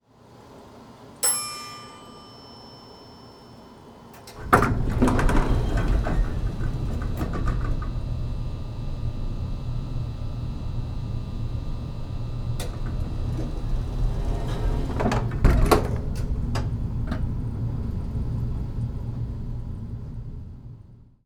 Elevator ding door open close, noisy, 10
ding elevator opening sound effect free sound royalty free Sound Effects